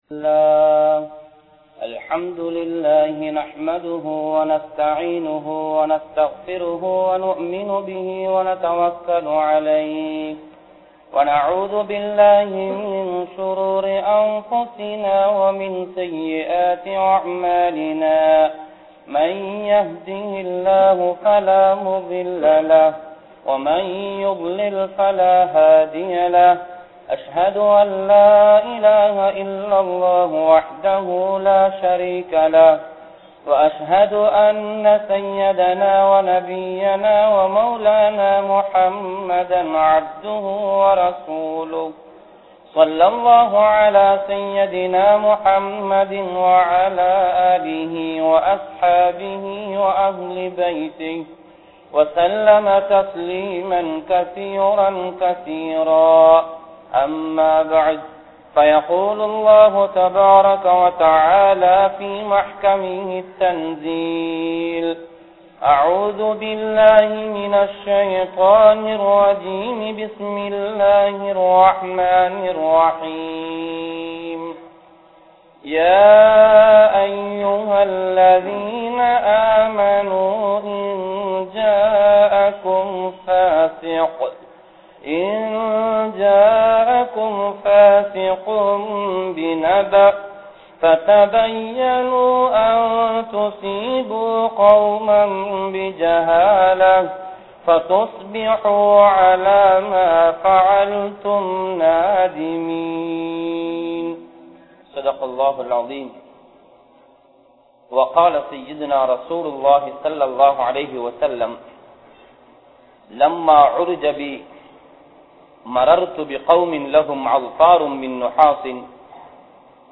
Paavaththin Vilaivuhal (பாவத்தின் விளைவுகள்) | Audio Bayans | All Ceylon Muslim Youth Community | Addalaichenai
Grand Jumua Masjith